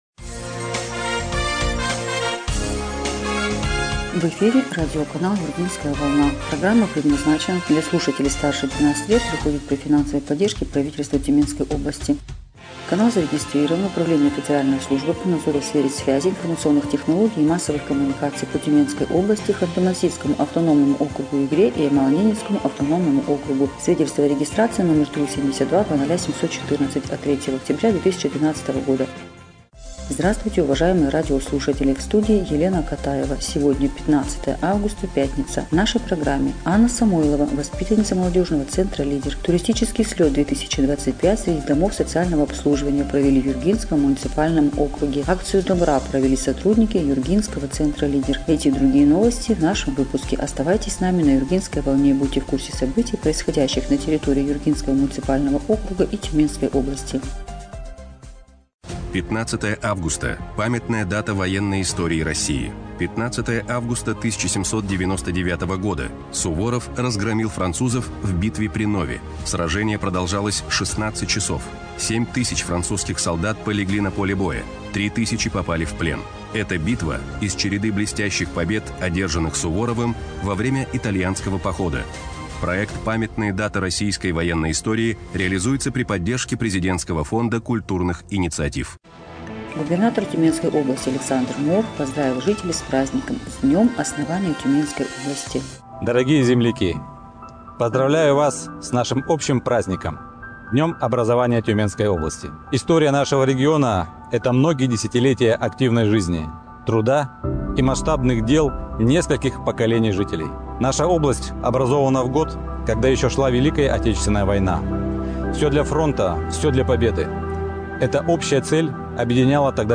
Эфир радиопрограммы "Юргинская волна" от 15 августа 2025 года